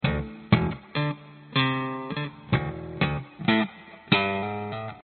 时髦的Bass Riff 3
描述：用电贝司（Fender Preci）弹奏时髦的拍击乐。
Tag: 贝斯 芬德 吉他 精确